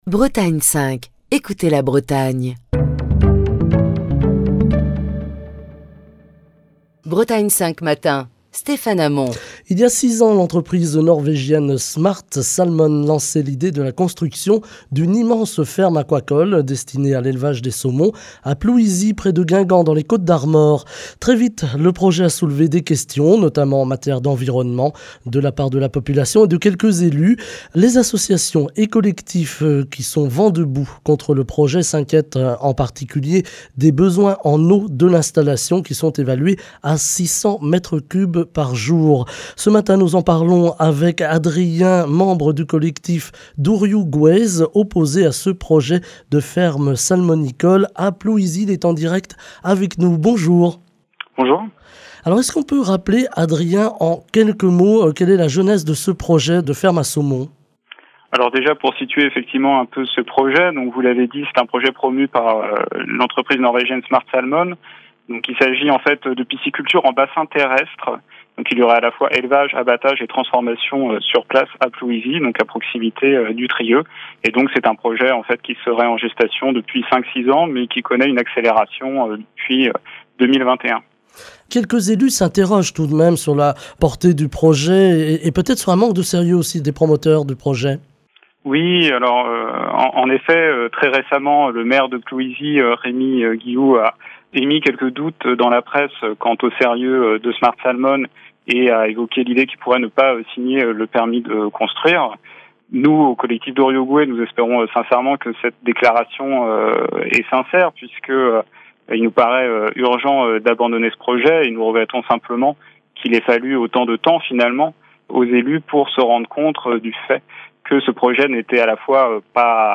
Les associations et collectifs qui sont vent de bout contre le projet s'inquiètent en particulier des besoins en eau de l'installation qui sont évalués à 600 m3 par jour. Ce matin, nous sommes en direct